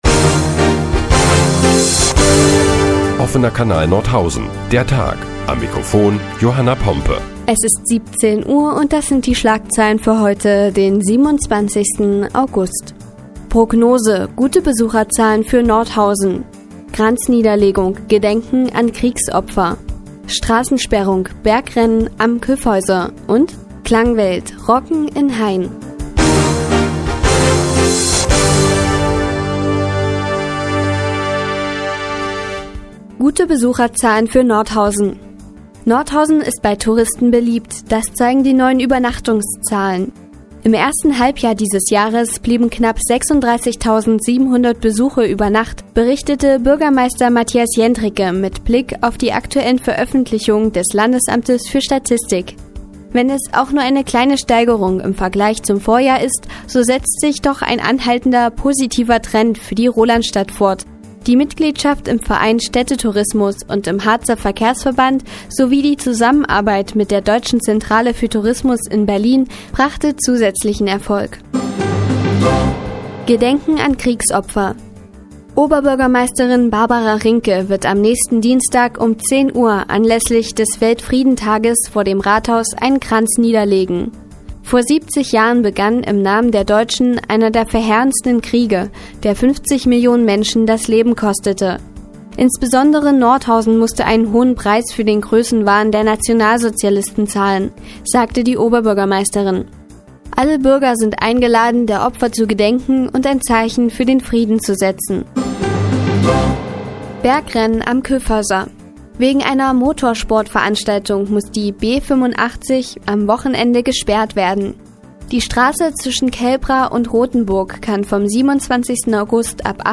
Die tägliche Nachrichtensendung des OKN ist nun auch in der nnz zu hören. Heute geht es unter anderem um das Bergrennen im Kyffhäuser und Gedenken an Kriegsopfer.